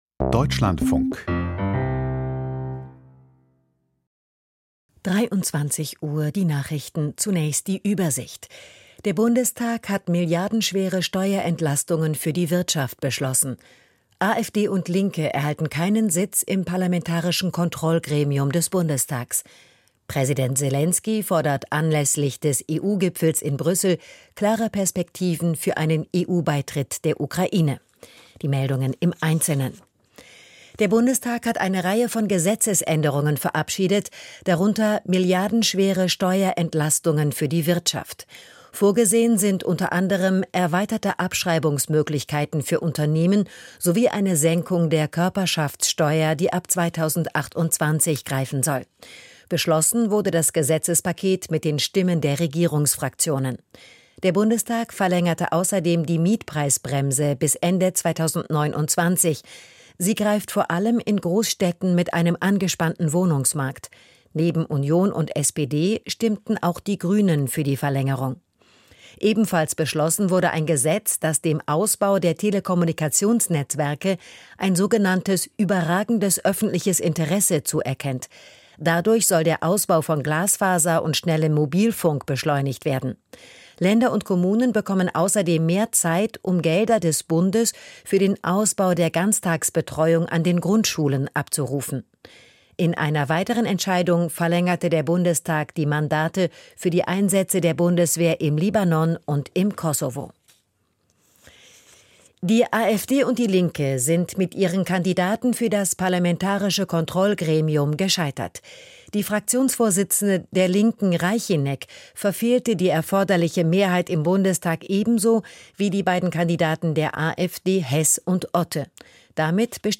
Die Nachrichten vom 26.06.2025, 23:00 Uhr
Aus der Deutschlandfunk-Nachrichtenredaktion.